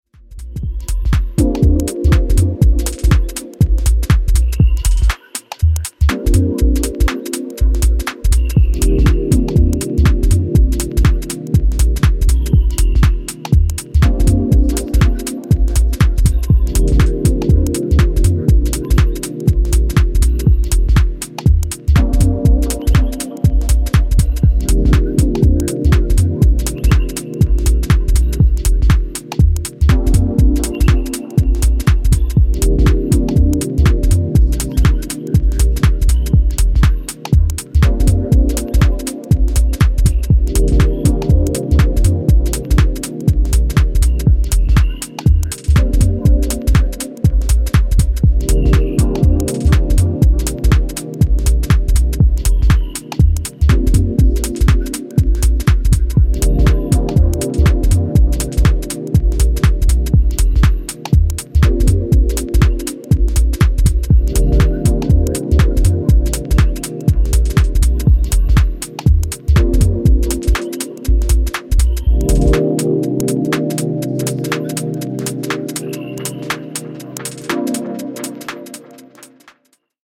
Both Tested And Approved On Various Dancefloors Worldwide.